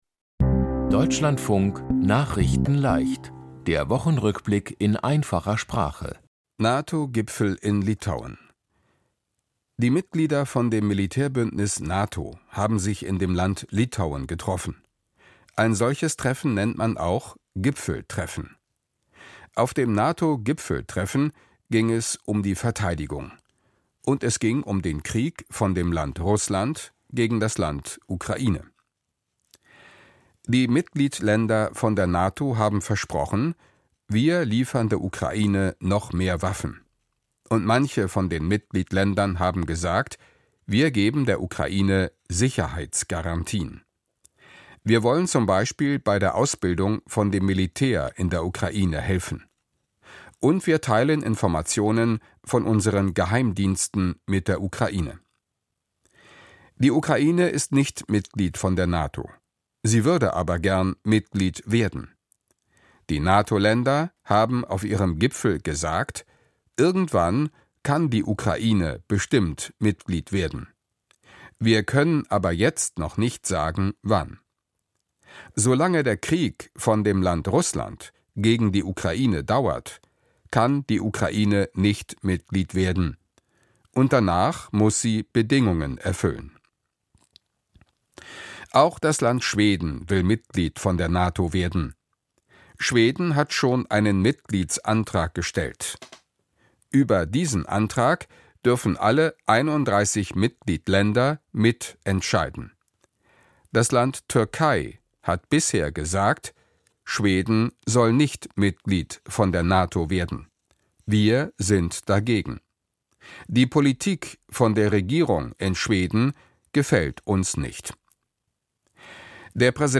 Die Themen diese Woche: NATO-Gipfel in Litauen, Europa-Parlament für Naturschutz-Gesetz, CDU hat neuen General-Sekretär, Heide Simonis gestorben, mehr Gewalt in Familien, Milan Kundera ist tot und Medaillen bei Para-WM. nachrichtenleicht - der Wochenrückblick in einfacher Sprache.